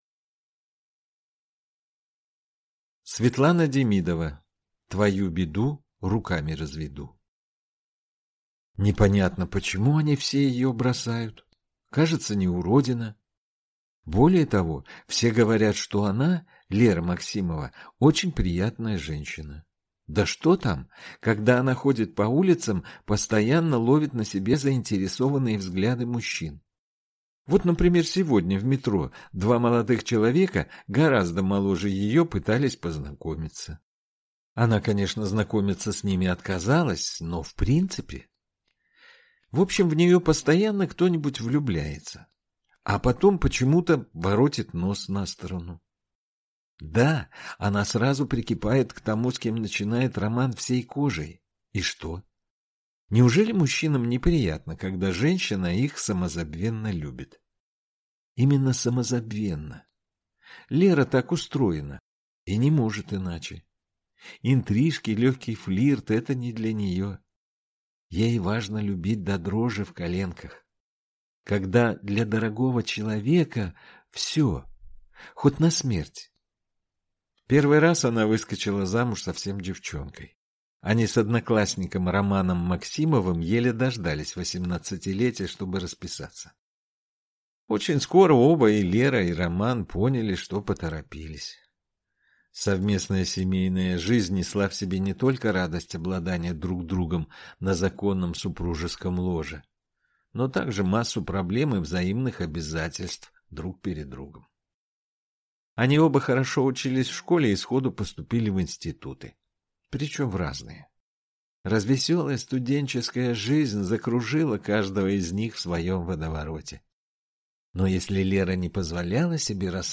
Аудиокнига Твою беду руками разведу | Библиотека аудиокниг